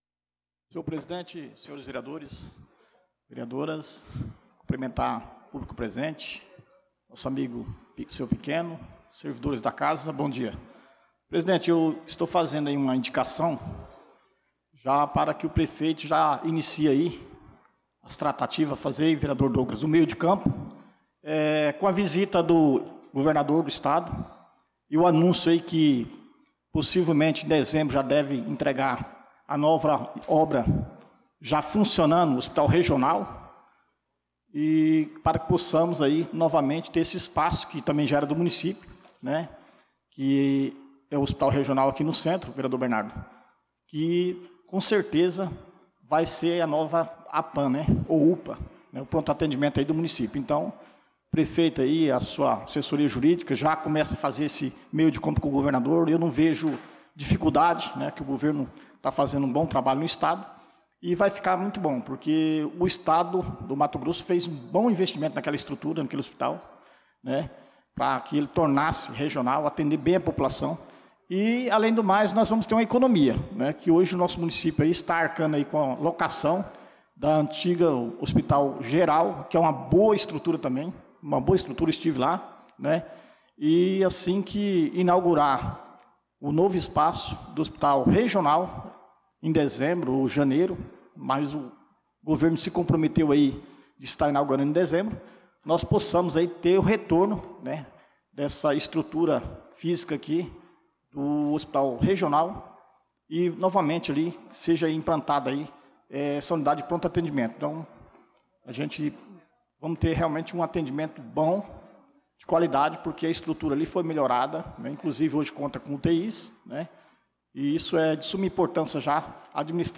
Pronunciamento do vereador Dida Pires na Sessão Ordinária do dia 09/06/2025